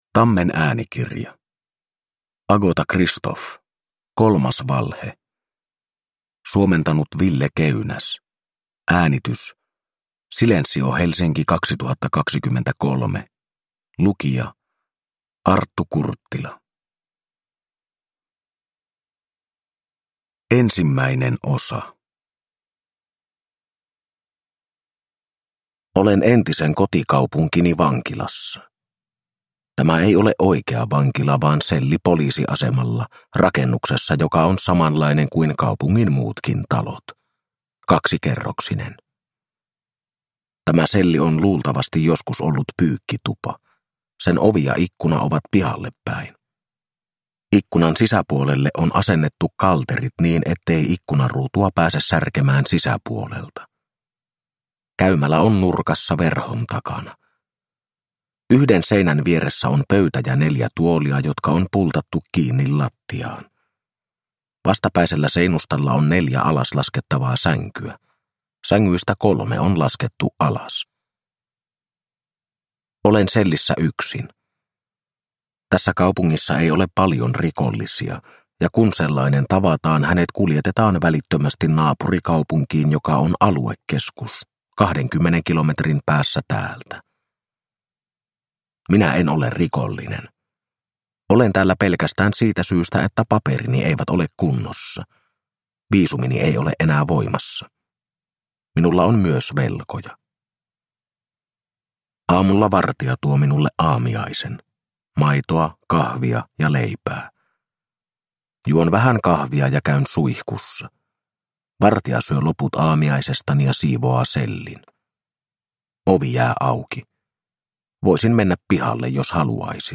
Kolmas valhe – Ljudbok – Laddas ner